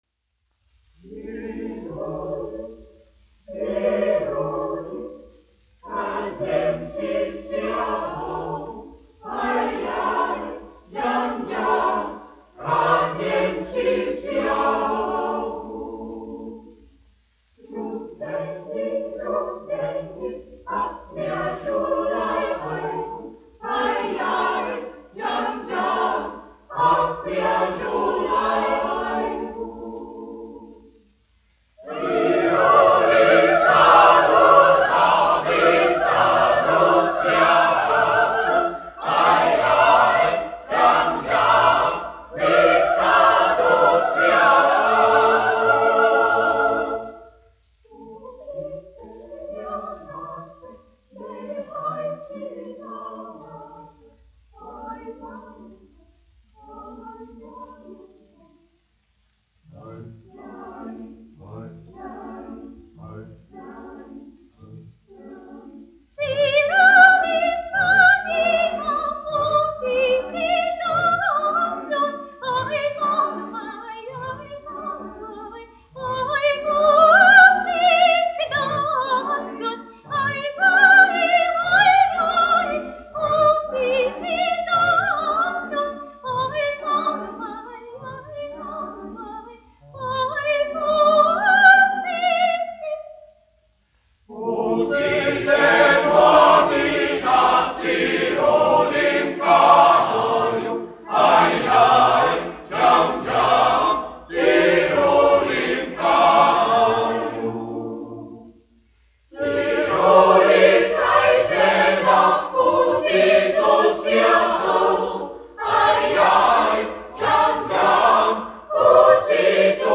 Emilis Melngailis, 1874-1954, aranžētājs
Reitera koris, izpildītājs
1 skpl. : analogs, 78 apgr/min, mono ; 25 cm
Latviešu tautasdziesmas
Kori (jauktie)
Skaņuplate